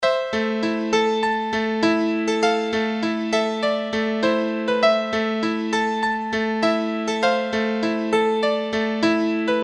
4个硬说唱歌手钢琴
Tag: 100 bpm Rap Loops Piano Loops 1.62 MB wav Key : Unknown